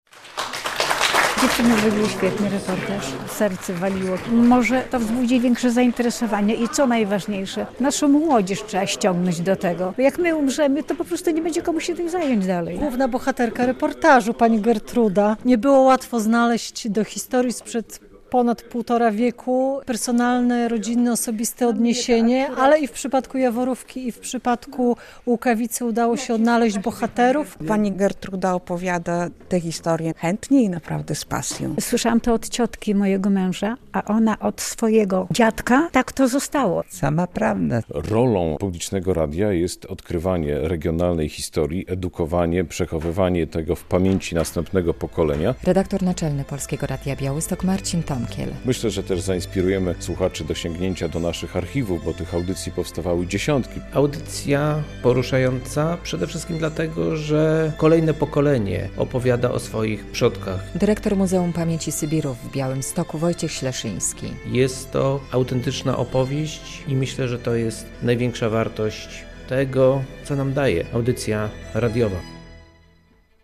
W środę (22.01) - w 162. rocznicę wybuchu powstania kilkadziesiąt osób uczestniczyło w Studiu Rembrandt Polskiego Radia Białystok w przedpremierowej prezentacji reportażu "Bez prawa powrotu".
relacja